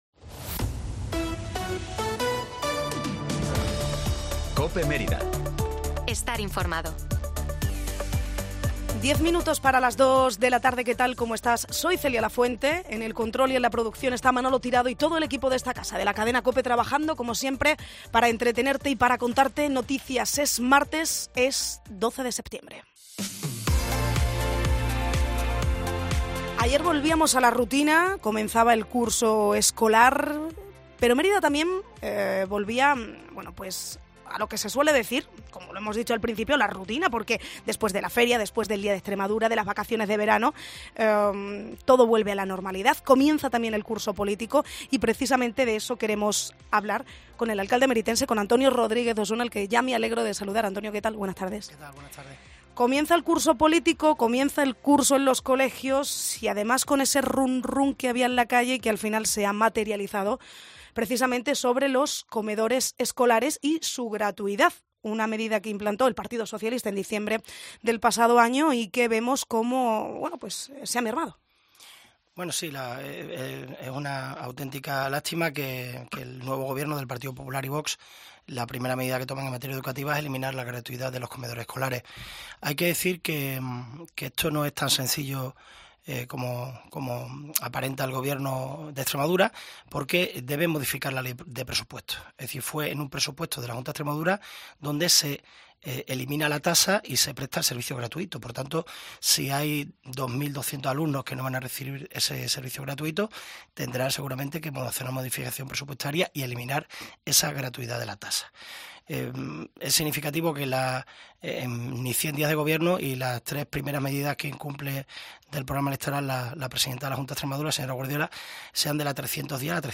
Entrevista a Antonio Rodríguez Osuna, alcalde de Mérida